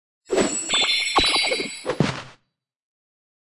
Media:anim_magical shelly.wav 动作音效 anim 查看其技能时触发动作的音效